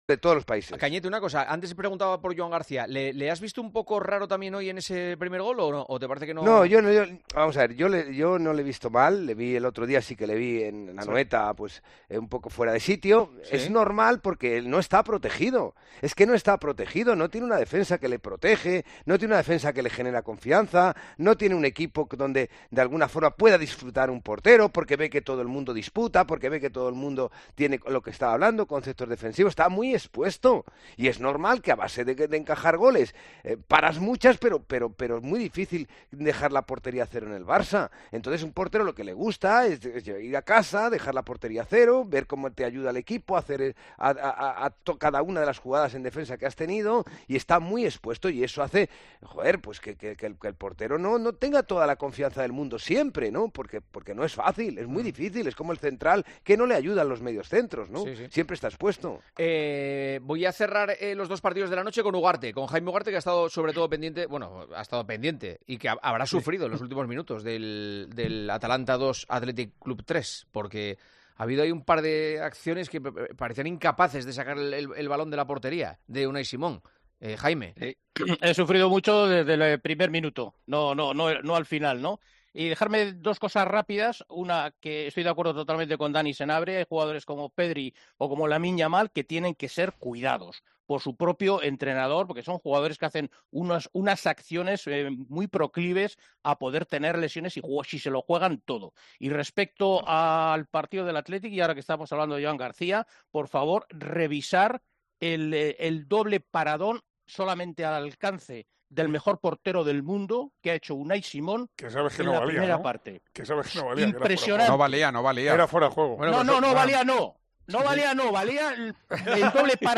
El exportero y comentarista deportivo analiza en El Partidazo de COPE la complicada situación del guardameta, que se encuentra muy expuesto por la falta de solidez defensiva
Santi Cañizares analiza la actuación del portero Joan García